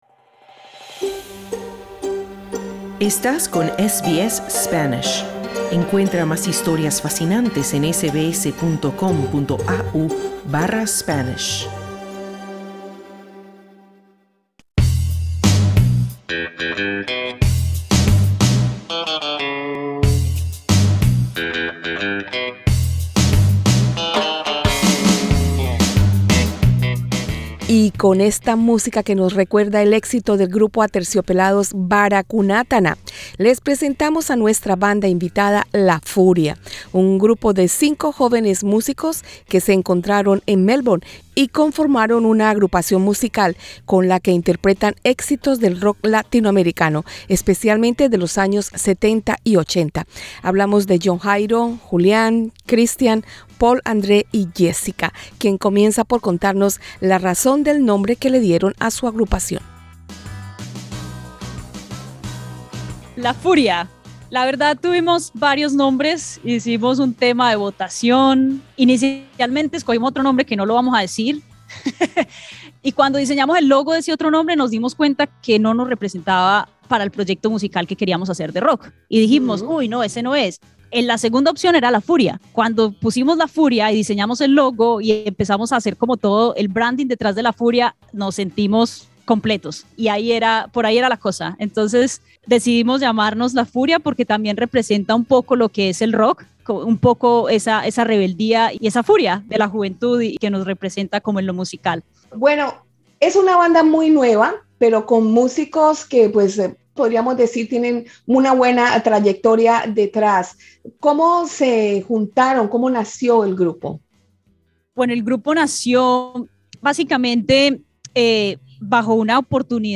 Cinco músicos colombianos que conforman la banda musical La Furia, hablan con SBS Spanish sobre sus próximas presentaciones en Melbourne.